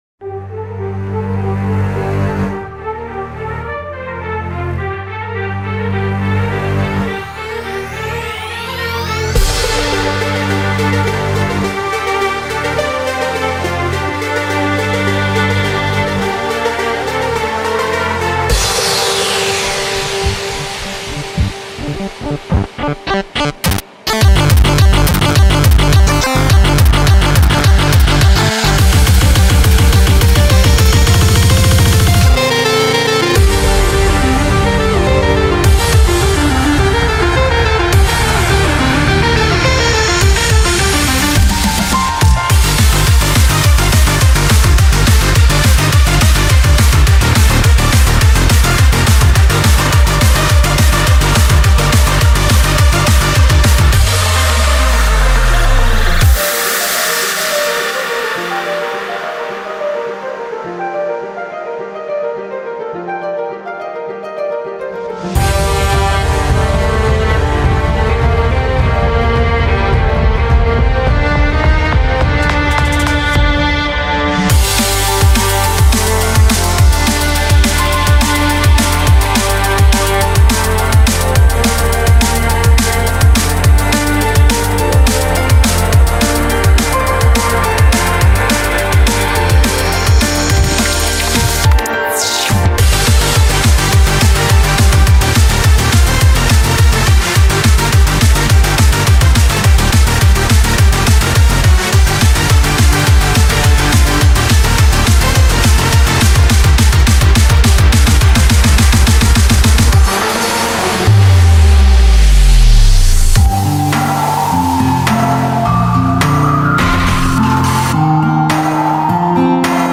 BPM105-210
Audio QualityPerfect (High Quality)